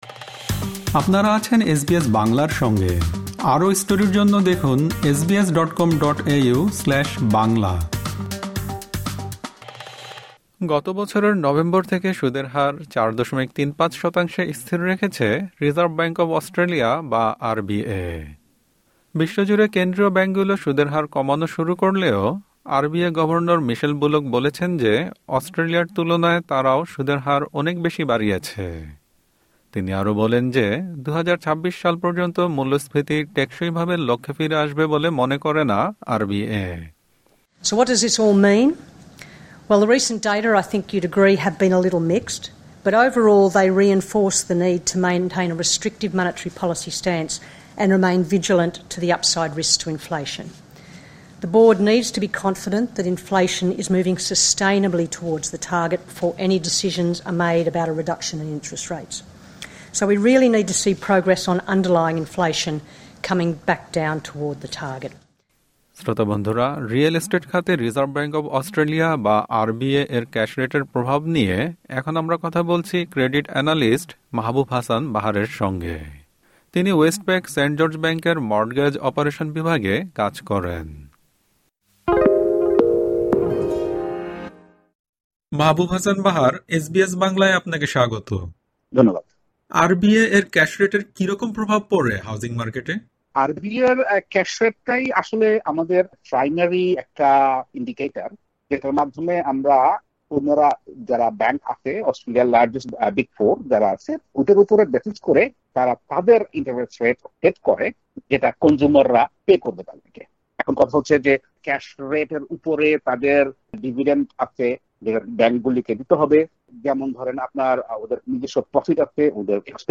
রিয়েল এস্টেট খাতে রিজার্ভ ব্যাংক অব অস্ট্রেলিয়া বা আর-বি-এ এর ক্যাশ রেটের প্রভাব নিয়ে এসবিএস বাংলার সঙ্গে কথা বলেছেন ক্রেডিট অ্যানালিস্ট